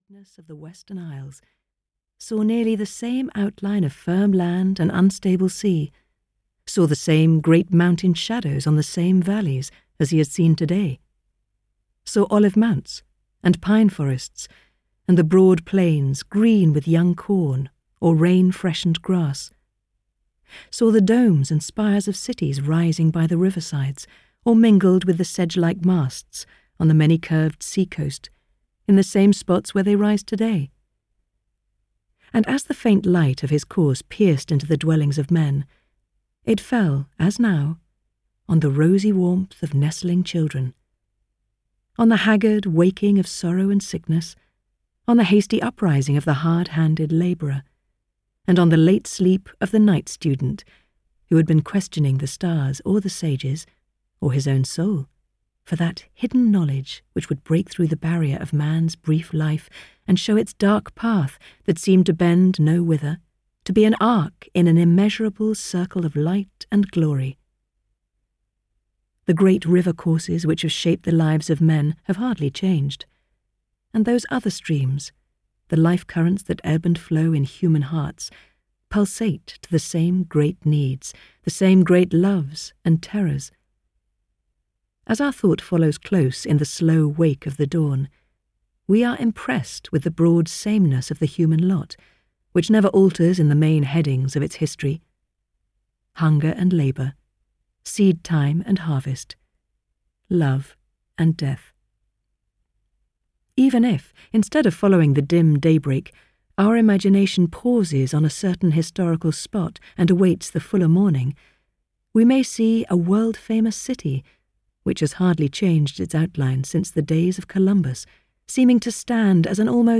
Romola (EN) audiokniha
Ukázka z knihy